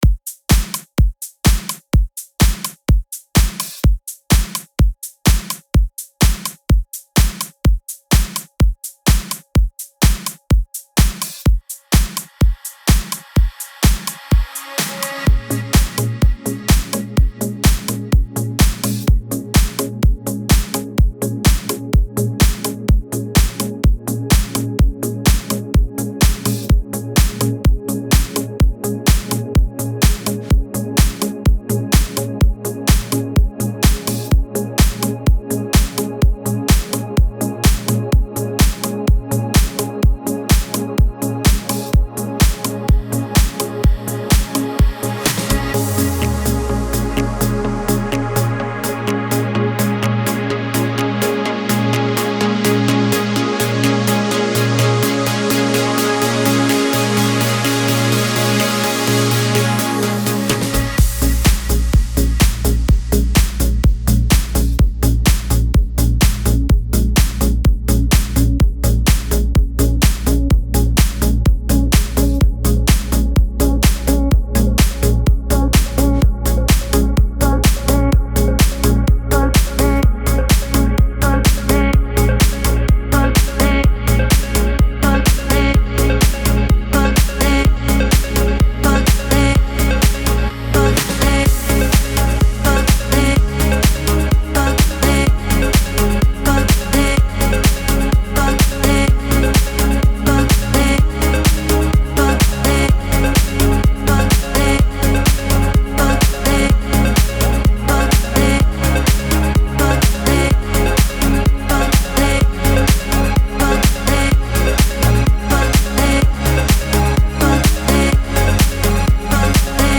Стиль: Melodic Progressive